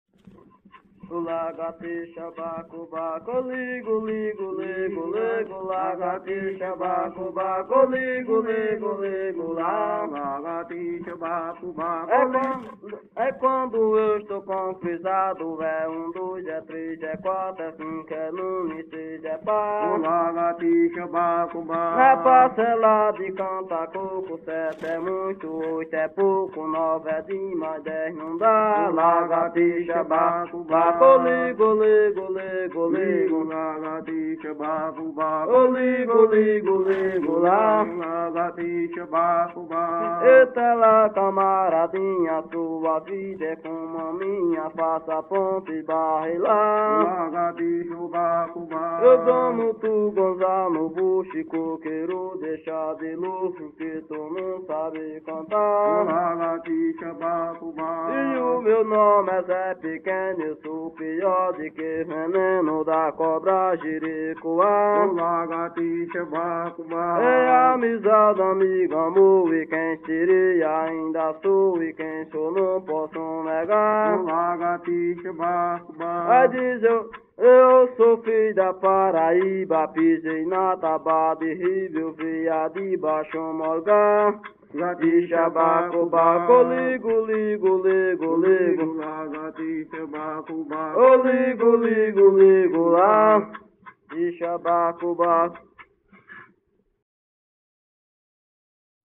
Coco embolada -“”Oh ligo, ligo, ligo”” - Acervos - Centro Cultural São Paulo